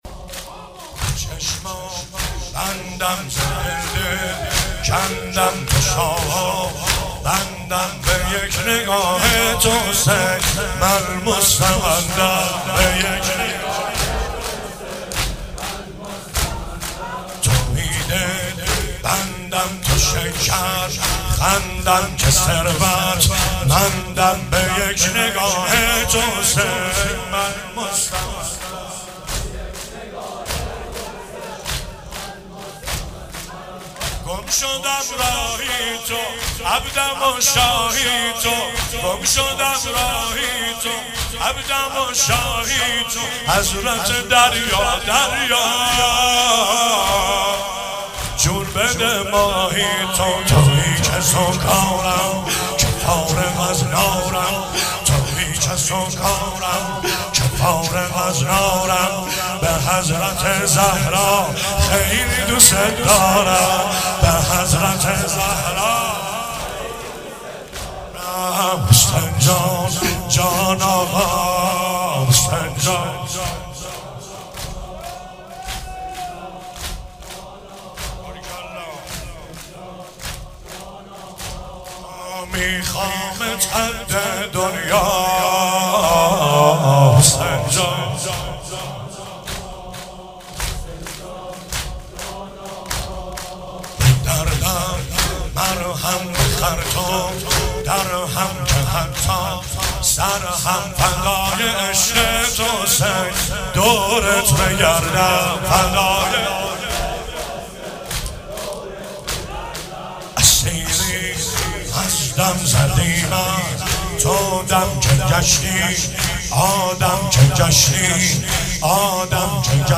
شب سوم رمضان 95، حاح محمدرضا طاهری
واحد، زمینه
09 heiat alamdar mashhad.mp3